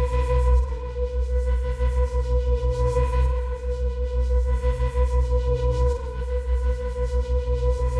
Index of /musicradar/dystopian-drone-samples/Tempo Loops/90bpm
DD_TempoDroneB_90-B.wav